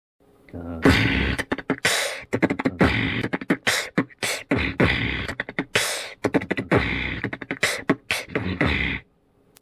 3) bm-dbdb-kch-dbdb-bm-dbdb-kch-b-kch-b..
Бит 3 - похоже больше на фристайл..